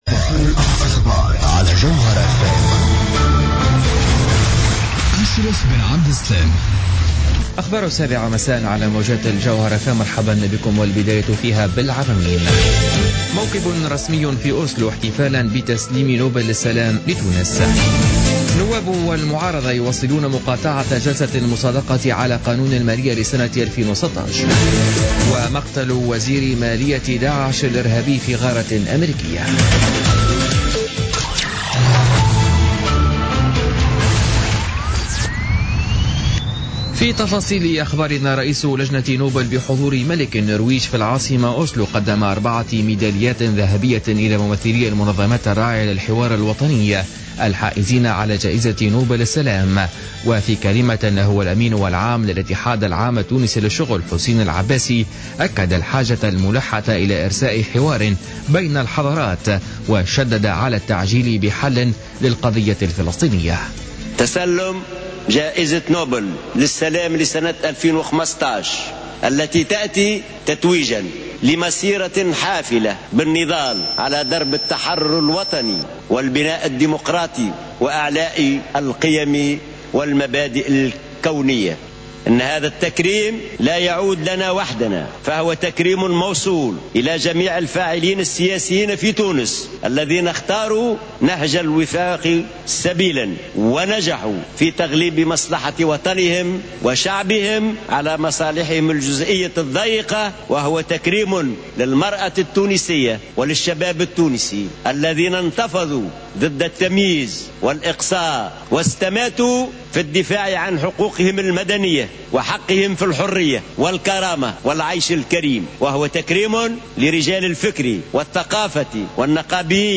نشرة أخبار السابعة مساء ليوم الخميس 10 ديسمبر 2015